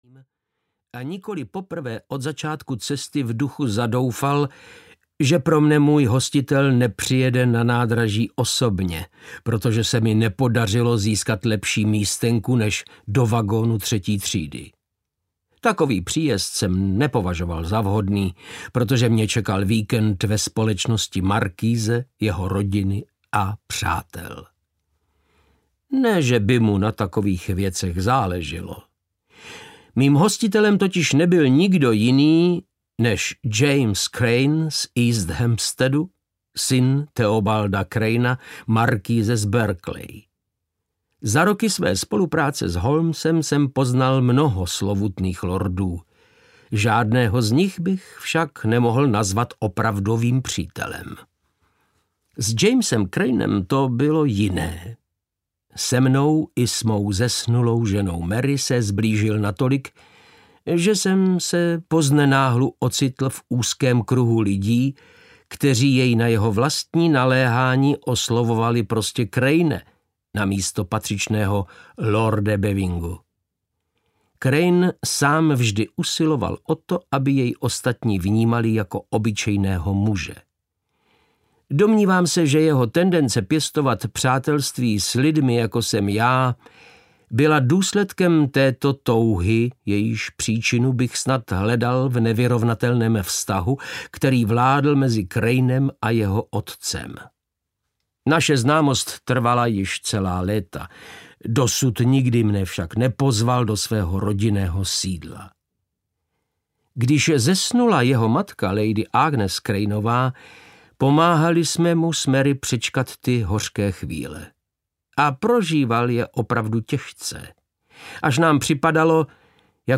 Sherlock Holmes a Rudá věž audiokniha
Ukázka z knihy
• InterpretVáclav Knop